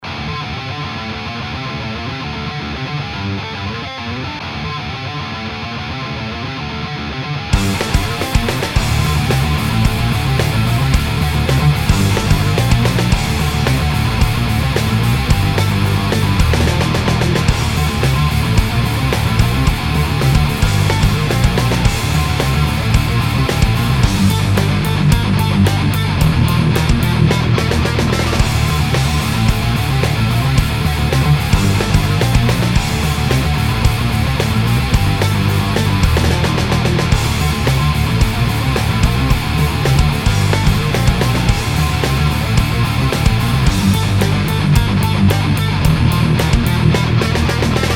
Je viens d'enregistrer la de suite, un petit riff pour etayer mes propos sur l'équilibre du mix quand on enregistre avec le pod (désolé c'est pas du métal):
La démo (mp3) (enregistré avec un podXT pro en v2.14 avec le micro grave d'une LesPaul BFG Gary Moore, un P90. Le pod XT pro est en mode carte son USB direct dans Cubase, et il n'y a aucun traitement sur les pistes de grattes. La batterie, c'est du bon vieux DFH en VSTi)
les basses: la basse est par définition un instrument sourd, le point crucial d'un mix est de lui libérer de l'espace, or là ton son de gratte est bien trop chargé en fréquences basse, ce qui fait que l'instrument basse devient inaudible dans la plage de fréquences dans laquelle elle a son assise, du coup ca fait un peu bouilli pas précise du tout, donc deja il faut faire ca, equaliser la guitare et lui rabotter les basses pour laisser la place à la basse.
Ta gratte a en plus d'avoir trop de basses, deux artefacts pas agréables du tout à l'écoute à 10 et à 13KHz qui donnent à la longue une fatigue auditive, on fini par n'entendre qu'un espèce de "pshhhhhhhhhh"